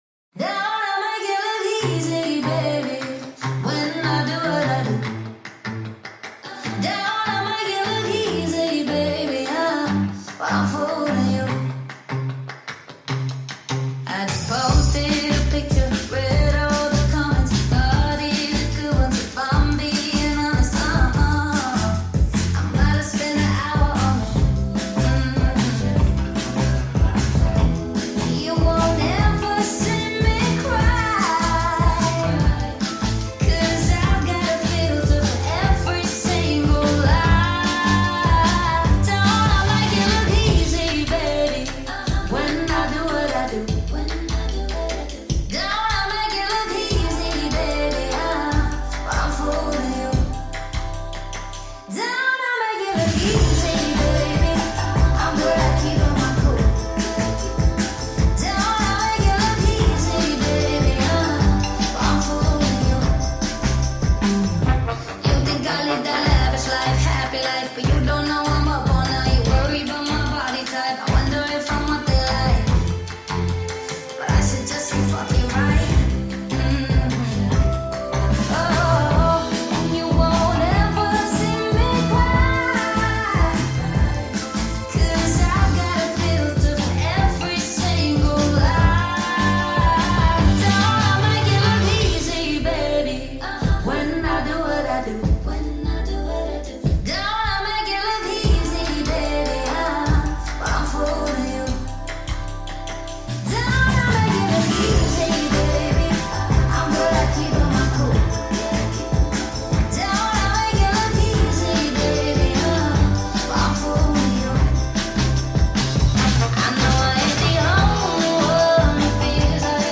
8D Audio